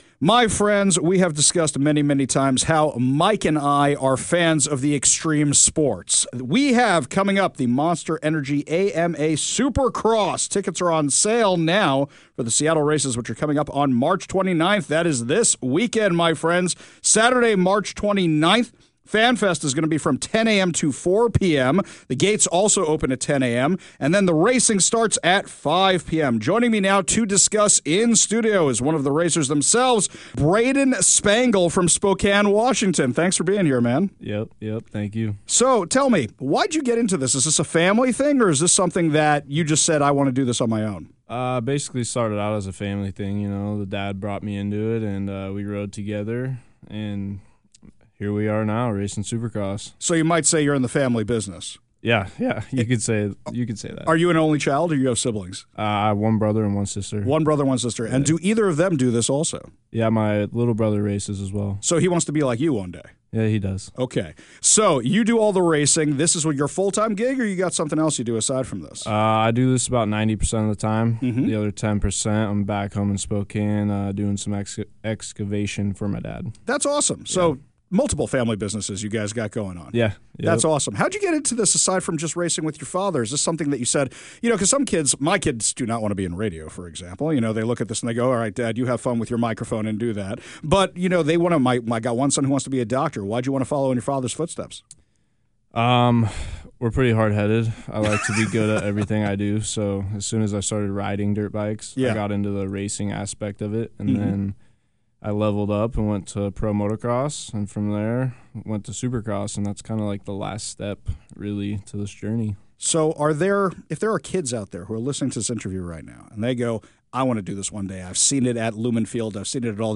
Supercross rider